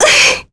Laias-Vox_Damage_kr_02.wav